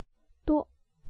Hók-ciŭ-uâ [huʔ˨˩ tsiu˥˧ ua˨˦˨]